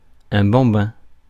Ääntäminen
Synonyymit bébé enfant mouflet Ääntäminen France: IPA: /bɑ̃.bɛ̃/ Haettu sana löytyi näillä lähdekielillä: ranska Käännös Ääninäyte Substantiivit 1. kid US 2. toddler US 3. minnow Suku: m .